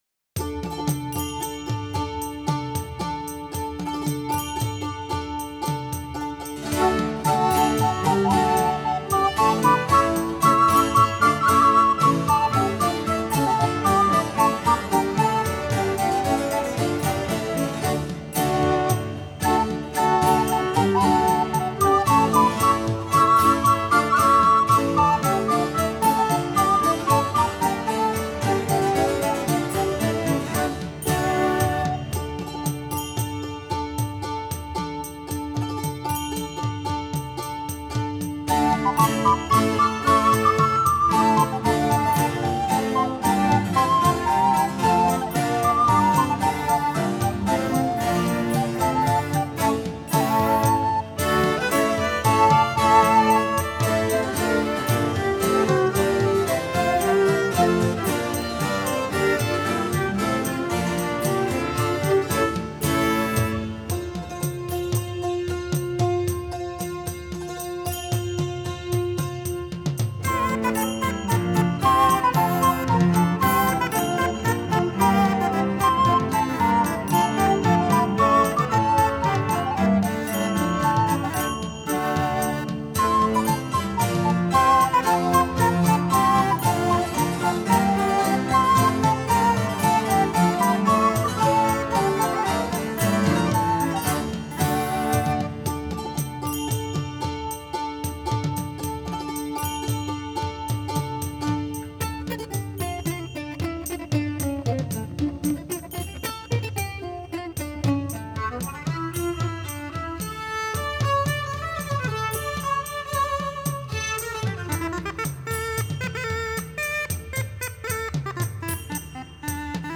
08-Gagliarda.m4a